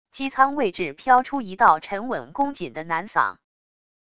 机舱位置飘出一道沉稳恭谨的男嗓wav音频